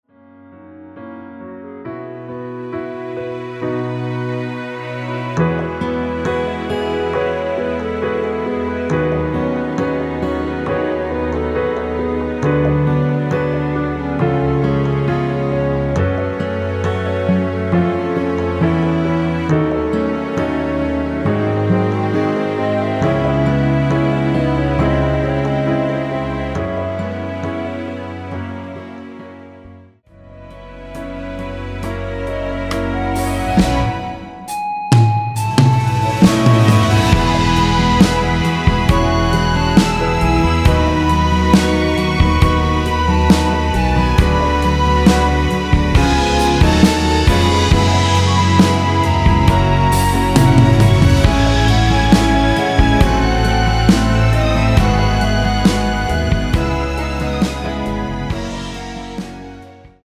전주가 길어서 미리듣기는 중간 부분 30초씩 나눠서 올렸습니다.
원키 멜로디 포함된 MR입니다.
멜로디 MR이라고 합니다.
중간에 음이 끈어지고 다시 나오는 이유는